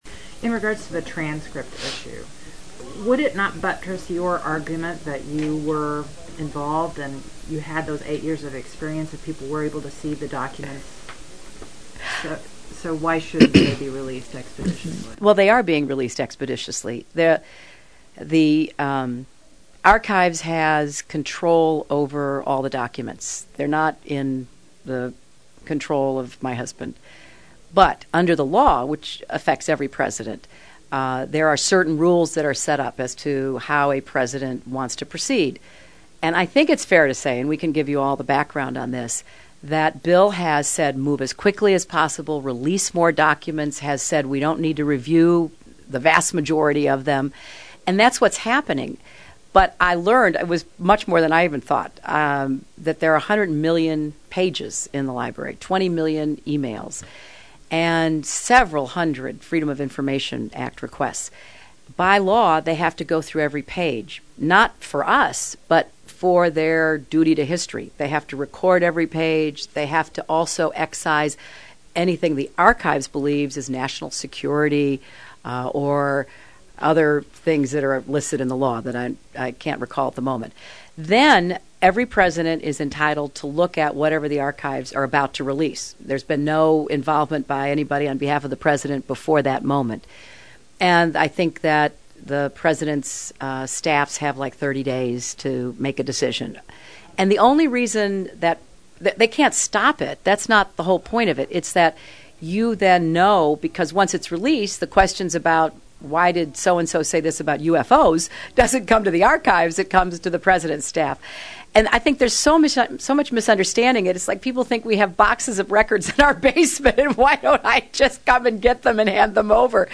This past weekend during an interview with Radio Iowa, Clinton said the U.S. should generate much more electricity from alternative sources like wind turbines.